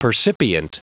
Prononciation du mot percipient en anglais (fichier audio)
Prononciation du mot : percipient